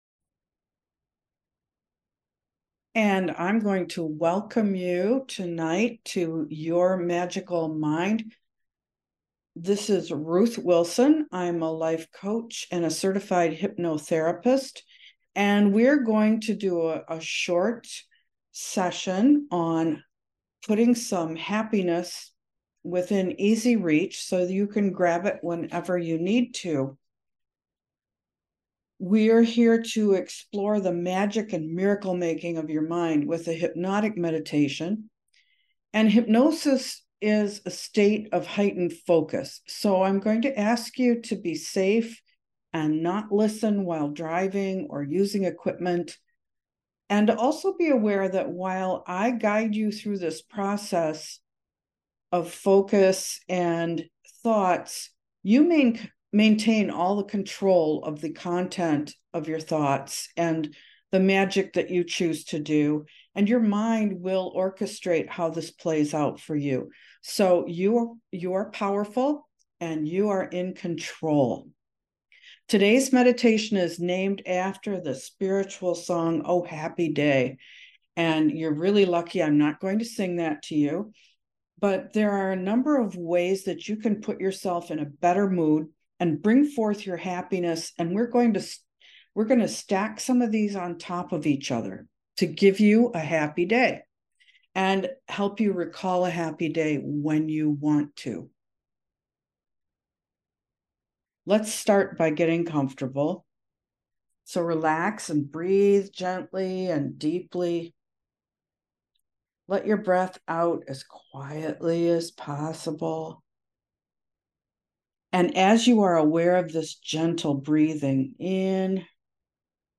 Discover the art of joyful meditation with this enlightening recorded hypnosis session, designed to help you bypass unnecessary stressors and foster positivity. allow yourself to tune into your healing energies, ultimately enhancing your mood, health, and encouraging the manifestation of your deepest desires.
Oh-Happy-Day-Hypnotic-Meditation-to-Open-Up-to-Your-Inner-Joy-so-You-Create-More-of-What-You-Want.mp3